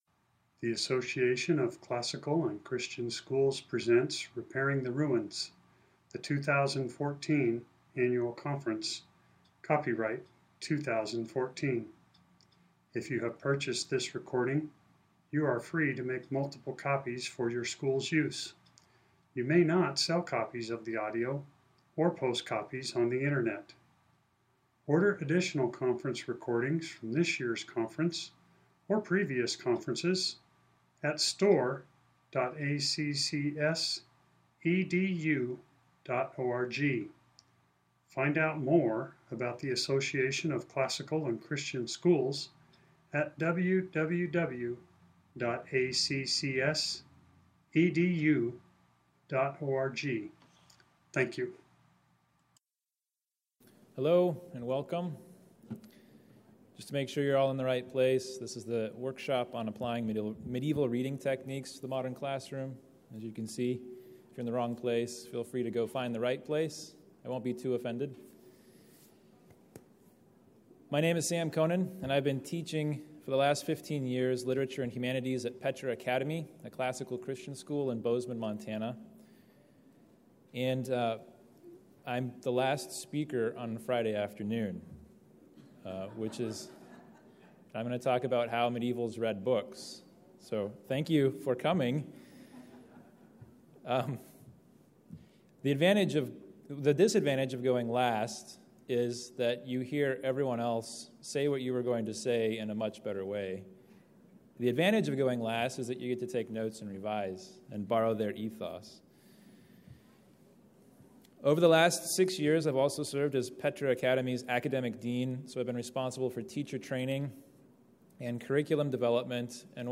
2014 Workshop Talk | 0:55:38 | All Grade Levels, Literature
The Association of Classical & Christian Schools presents Repairing the Ruins, the ACCS annual conference, copyright ACCS.